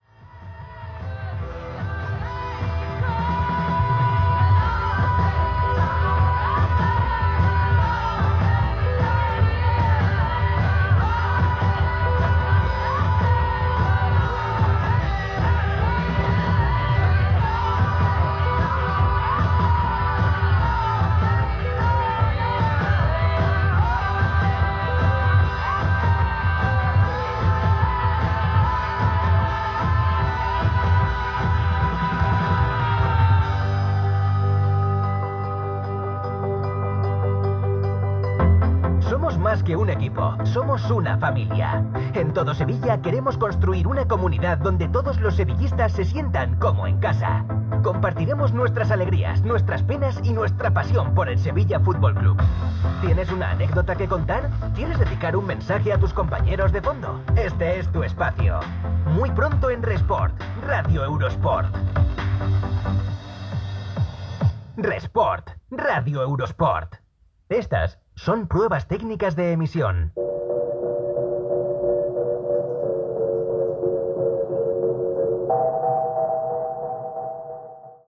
Promoció de "Todo Sevilla", identificació en proves tècniques d'emissió i tema musical
DAB